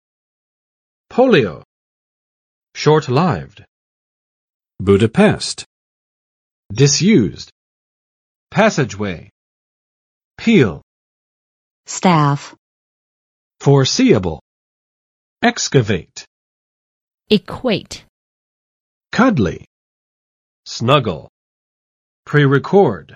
Vocabulary Test - May 13, 2020
[ˋpolɪo] n.【医】小儿麻痹症；脊髓灰质炎
[ˋʃɔrtˋlaɪvd] adj. 短命的；短暂的
[͵budəˋpɛst] n. 布达佩斯（匈牙利首都）
[dɪsˋjuzd] adj. 废弃不用的
[ˋpæsɪdʒ͵we] n. 通道；走廊